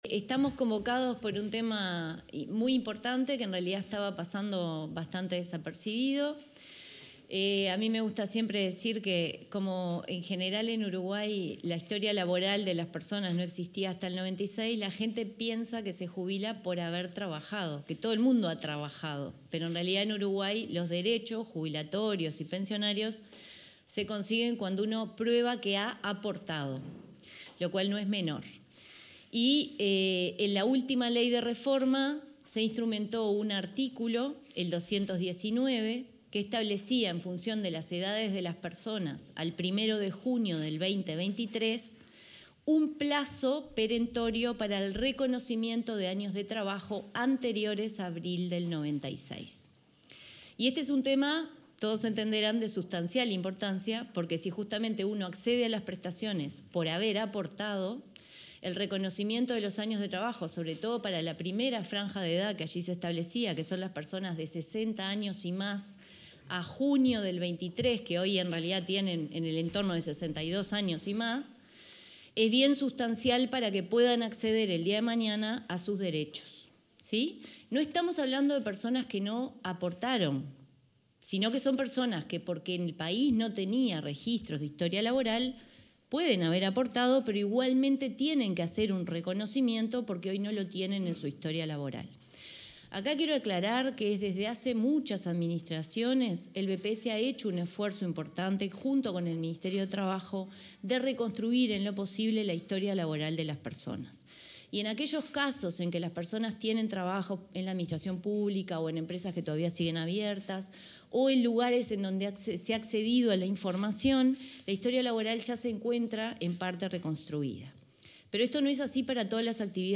Palabras de la presidenta de BPS, Jimena Pardo